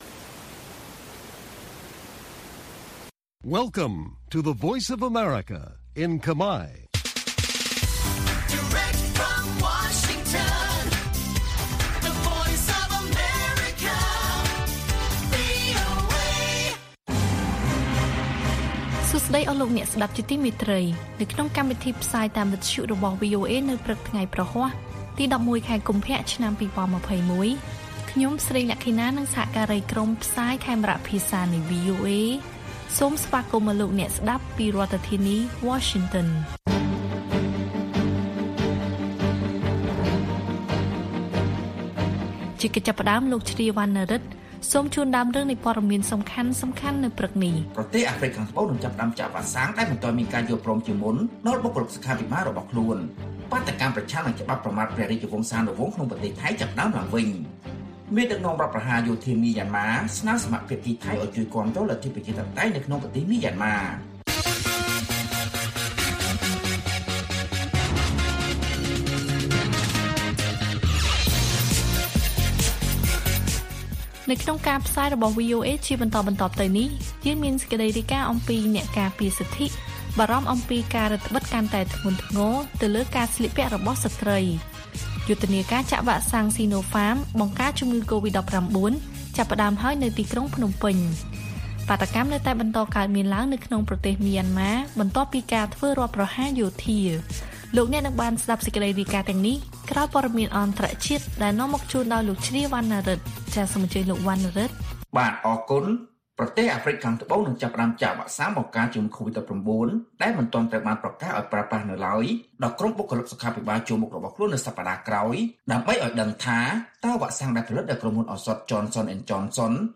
ព័ត៌មានពេលព្រឹក៖ ១១ កុម្ភៈ ២០២១